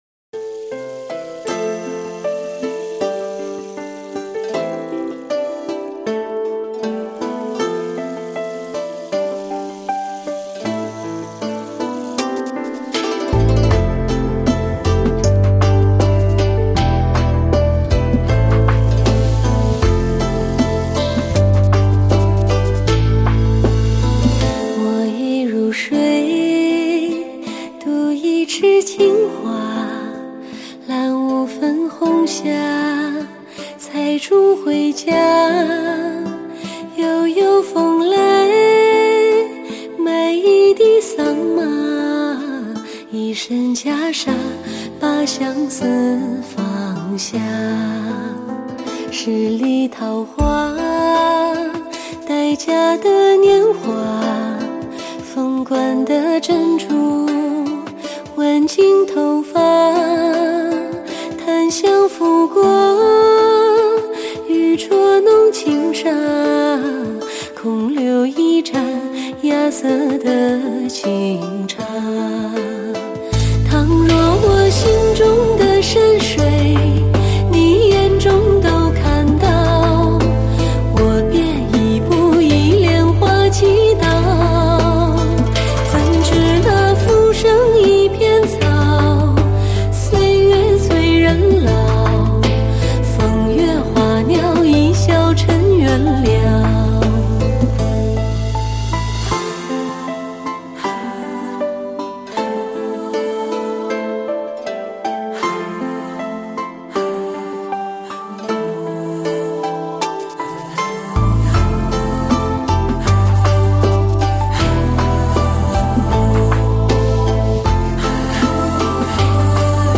禅意中国风